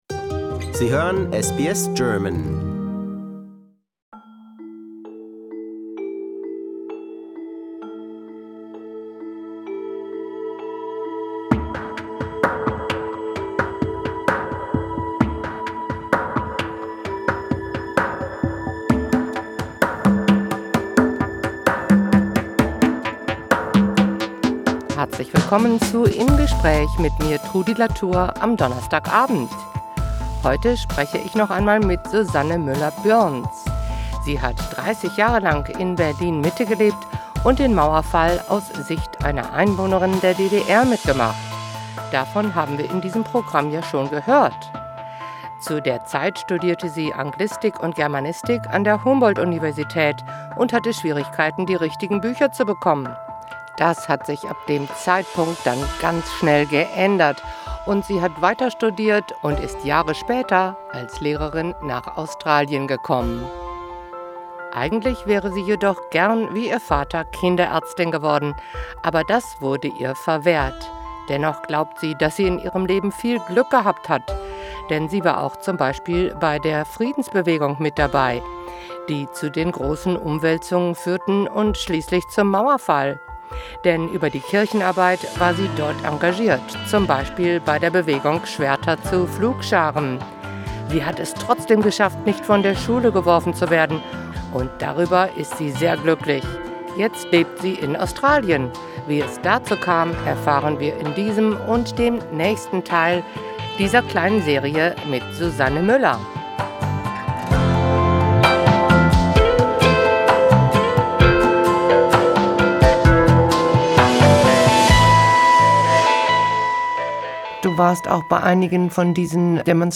Im Gespräch: Die Nacht in der die Grenze aufgemacht wurde